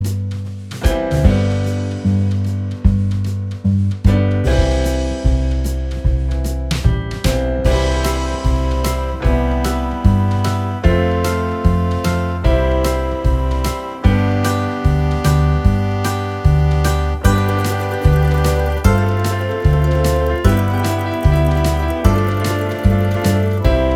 Minus All Guitars Pop (1970s) 4:10 Buy £1.50